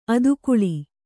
♪ adukuḷi